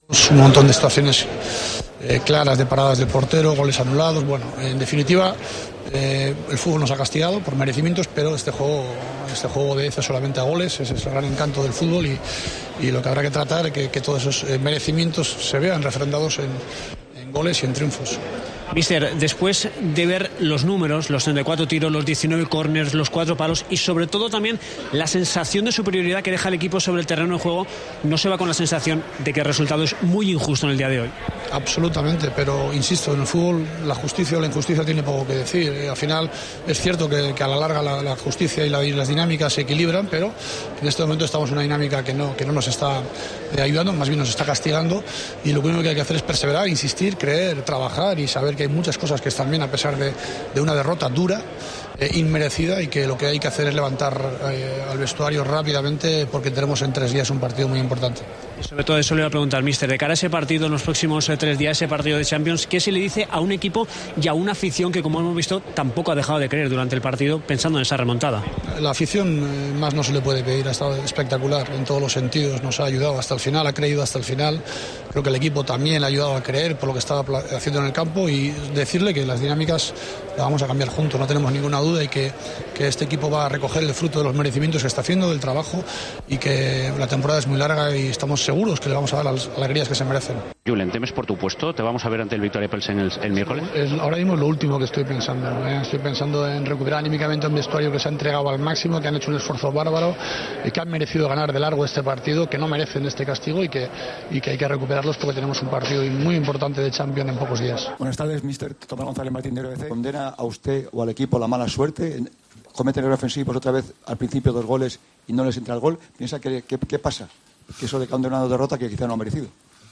El entrenador del Real Madrid habló tras el quinto partido consecutivo sin ganar.